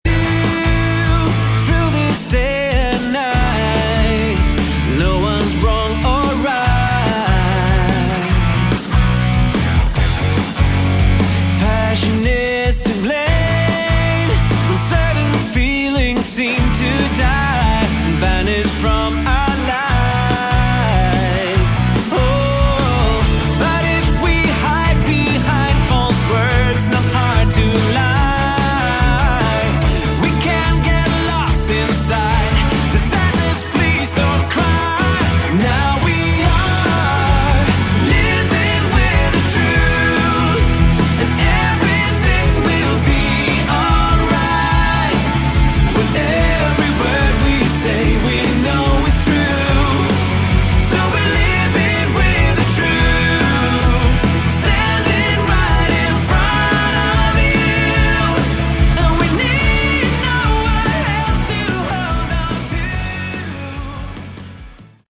Category: AOR
guitar, vocals, keyboards
bass
drums